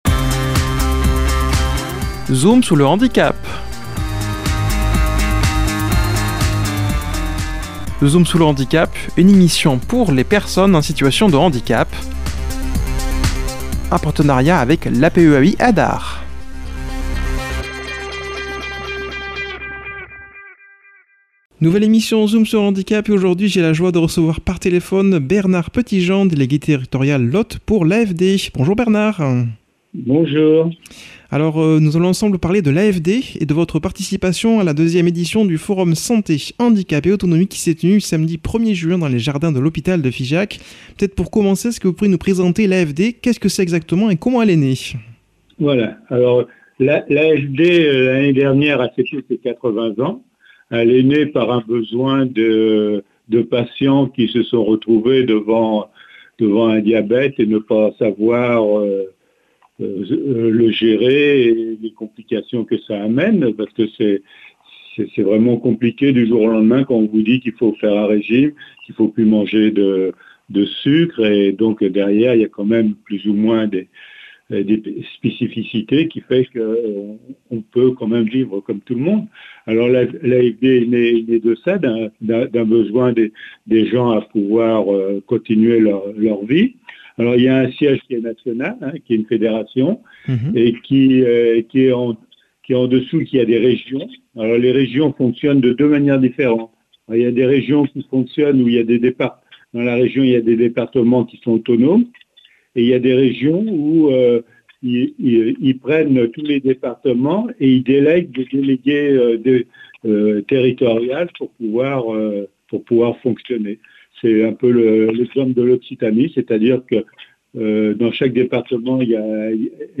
invité par téléphone